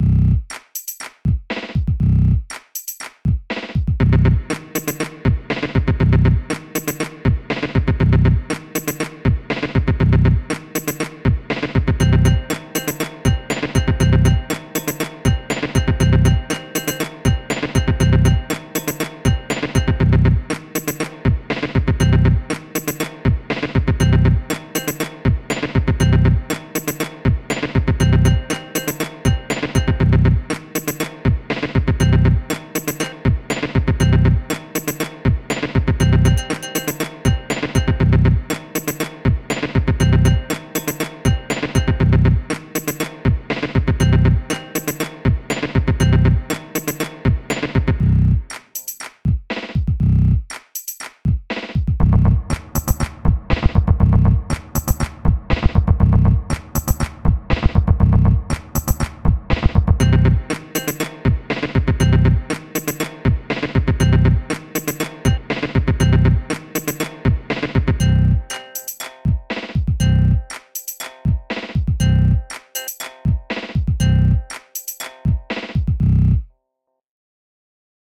Pieza de Electroclash
Música electrónica
melodía
sintetizador